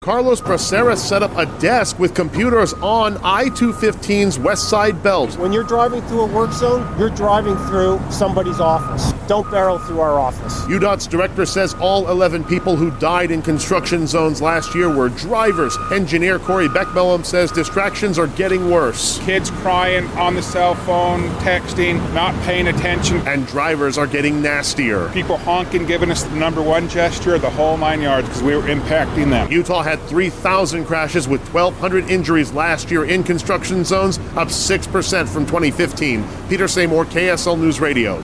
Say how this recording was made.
U-DOT’s executive director sets up shop on a busy freeway to tell drivers they have had a deadlier time in construction zones than the workers there.